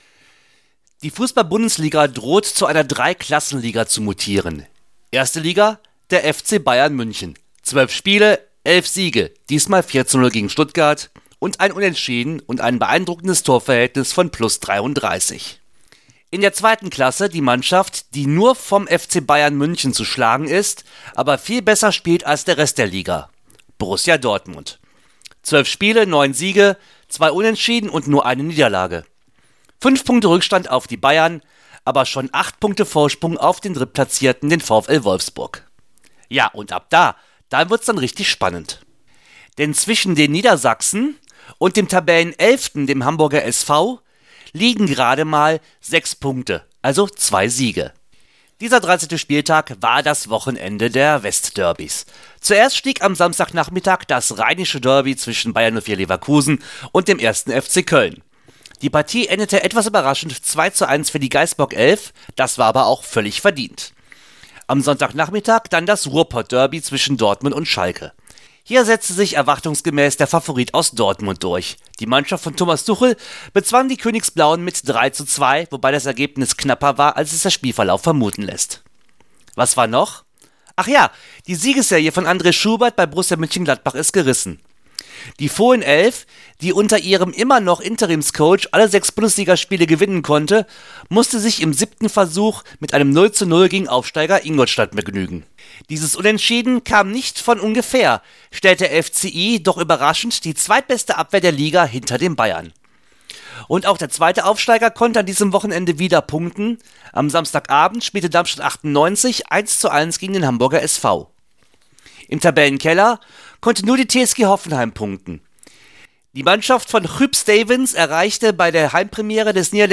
IMMER AM BALL – Der Bundesliga-Bericht